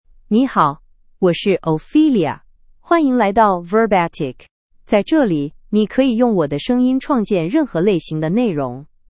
OpheliaFemale Chinese AI voice
Ophelia is a female AI voice for Chinese (Mandarin, Simplified).
Voice sample
Listen to Ophelia's female Chinese voice.
Female